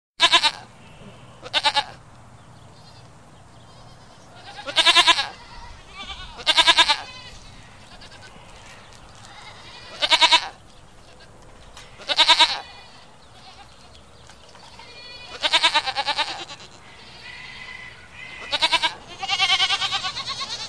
bleianie-kozy.mp3